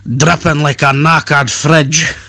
drippingfridge.mp3